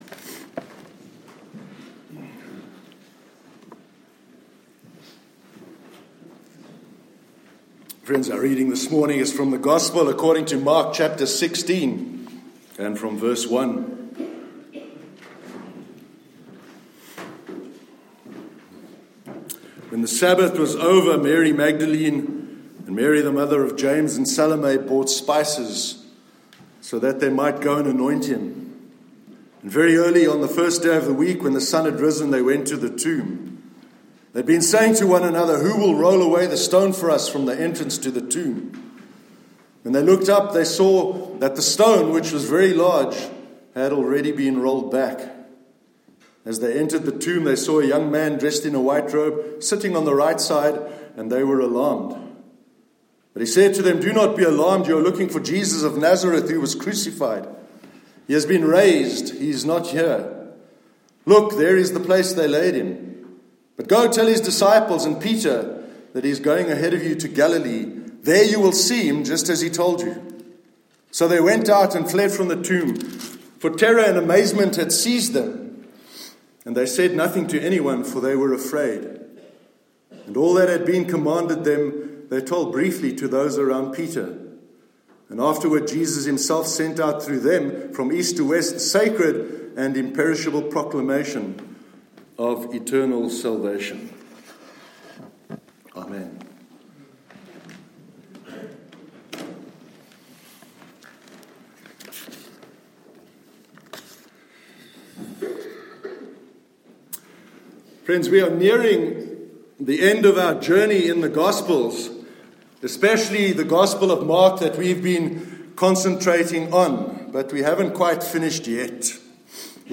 The Resurrection of Jesus- Sermon 28th April 2019 – NEWHAVEN CHURCH